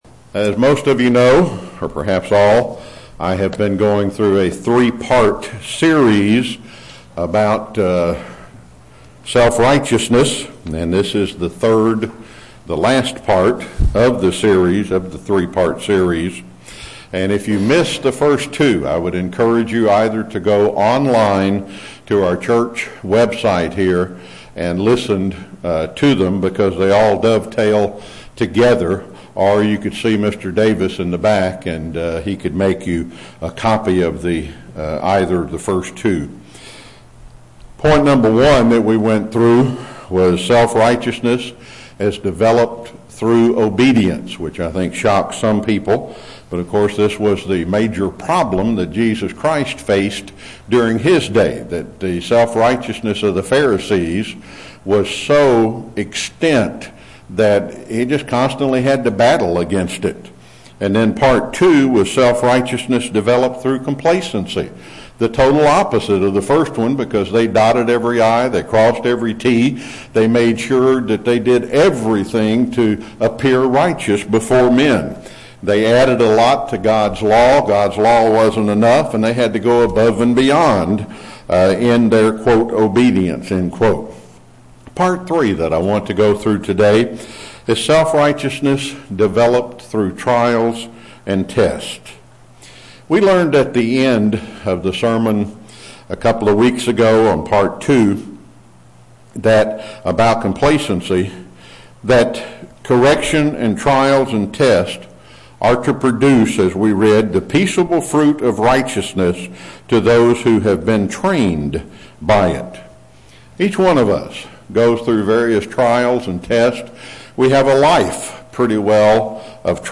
Given in Chattanooga, TN
UCG Sermon Studying the bible?